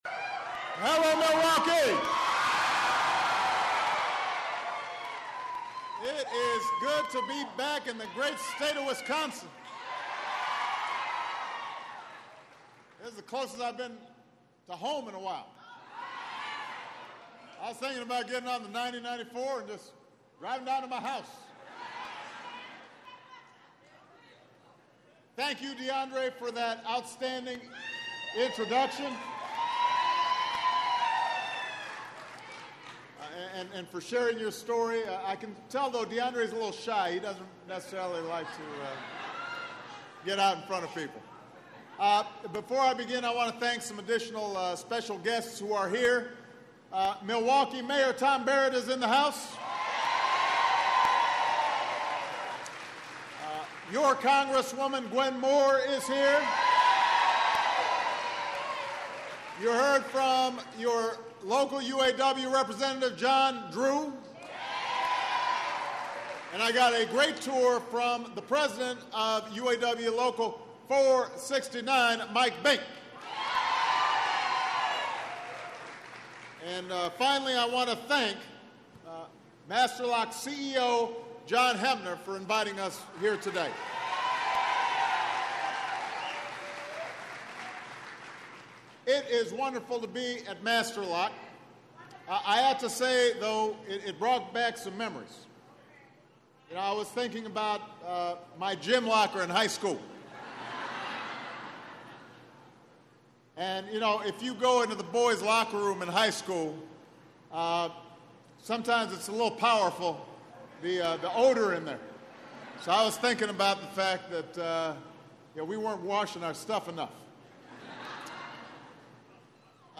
U.S. President Barack Obama speaks to employees at the Master Lock factory in Milwaukee, Wisconsin, on promoting American manufacturing industry